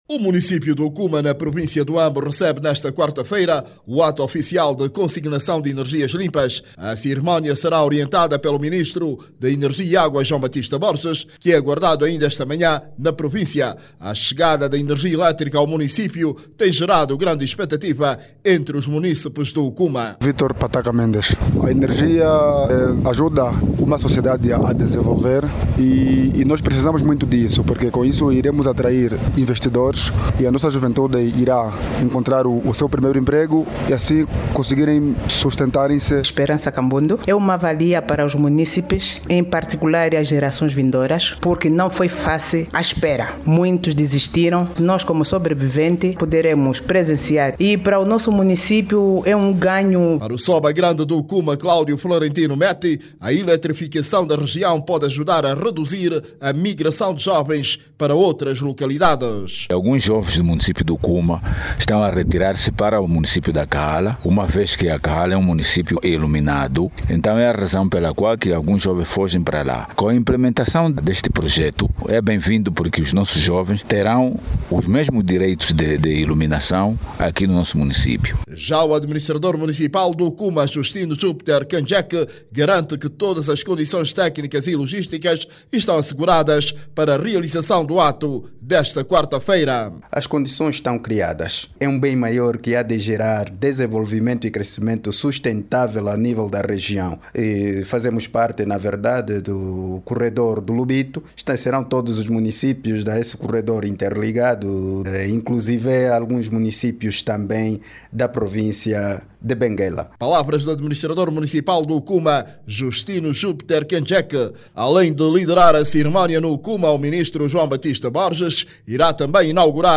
O município testemunha hoje, quarta-feira(03), o acto de consignação para a instalação de uma central de produção de energia limpa. Clique no áudio abaixo e ouça a reportagem